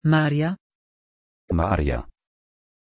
Aussprache von Maarja
pronunciation-maarja-pl.mp3